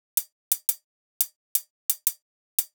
Hat loops (17).wav